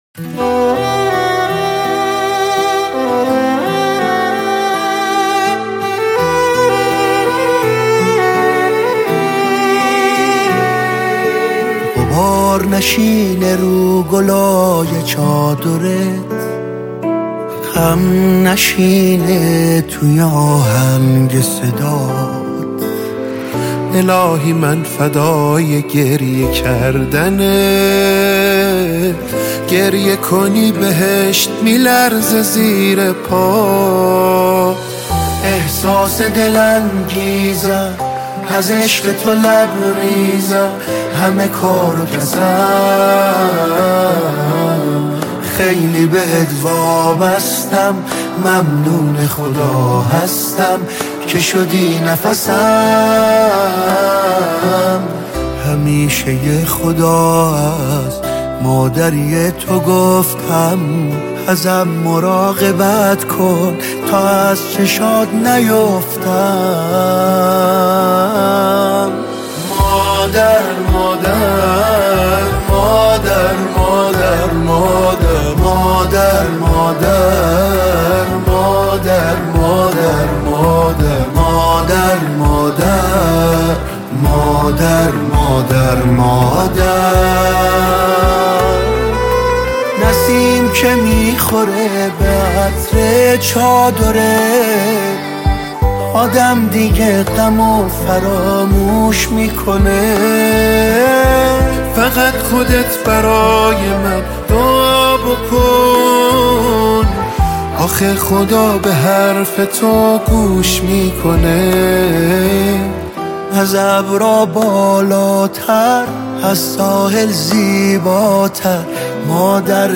به مناسبت ایام فاطمیه منتشر شد: